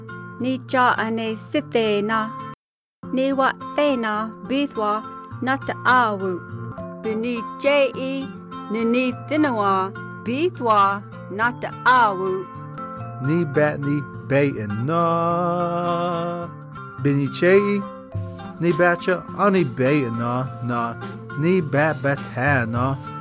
20 November 2017 at 12:38 pm I hear lexical tone, interdental stops/affricates, and a high central/back unrounded vowel.